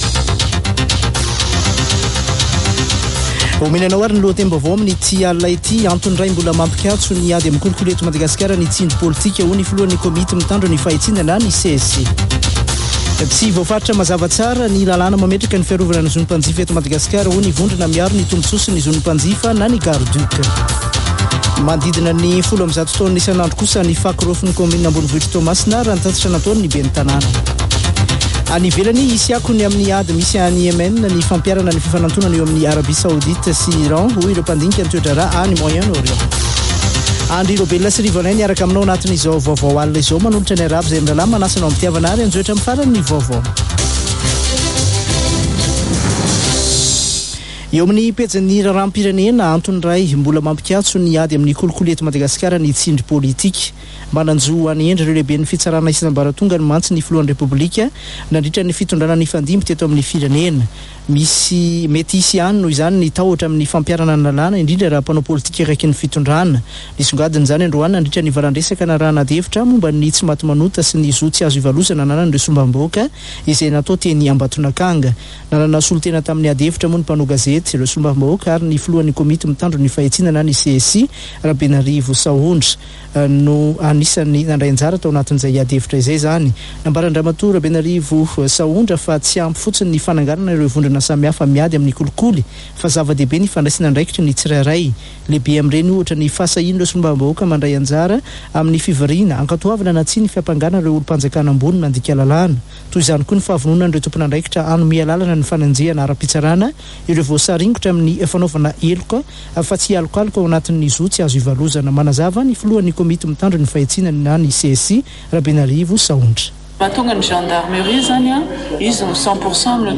[Vaovao hariva] Alarobia 15 marsa 2023